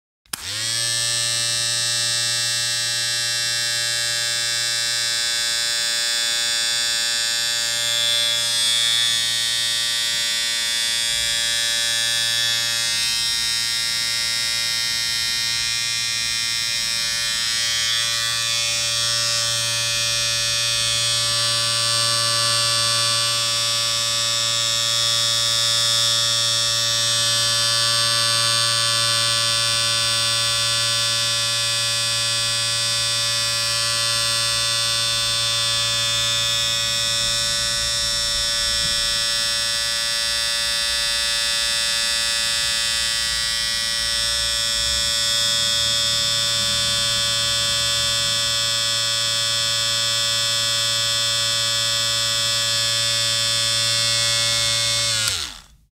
دانلود آهنگ ماشین ریش تراش 1 از افکت صوتی اشیاء
جلوه های صوتی
دانلود صدای ماشین ریش تراش 1 از ساعد نیوز با لینک مستقیم و کیفیت بالا